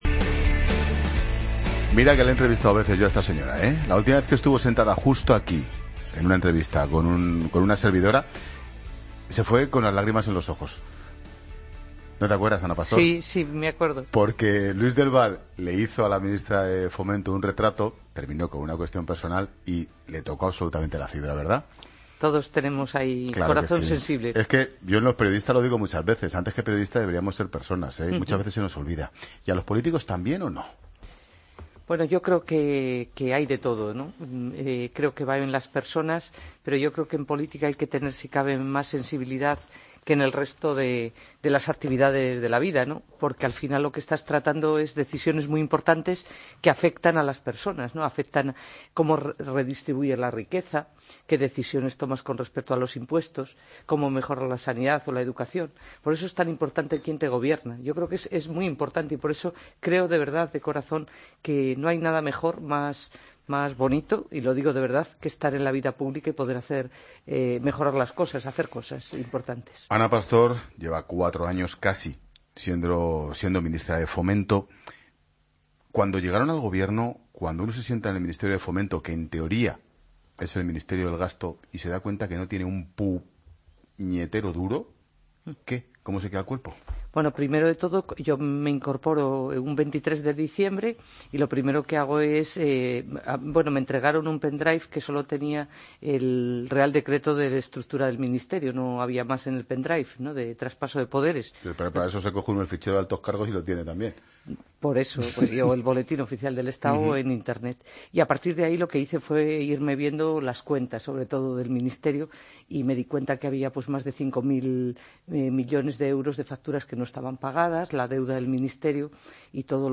AUDIO: Escucha la entrevista de Ana Pastor, ministra de Fomento, en La Tarde con Ángel Expósito